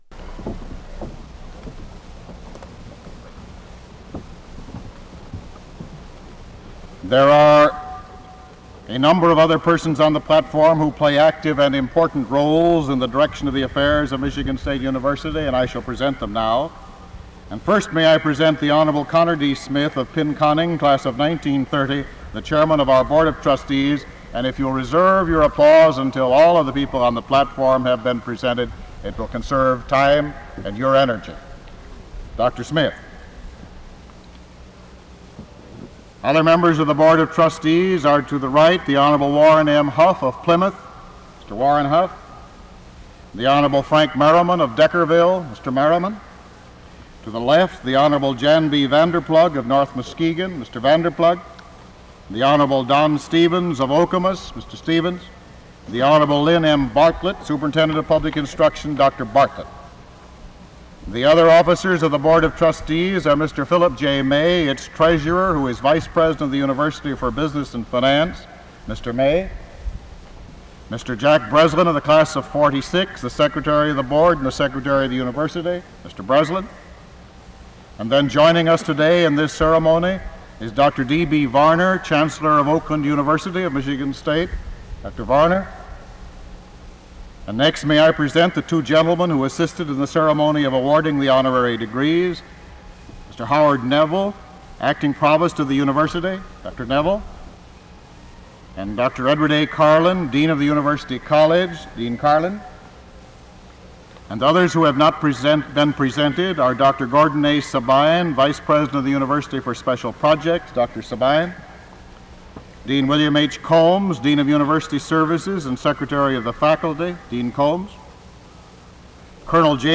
Original Format: Open reel audio tape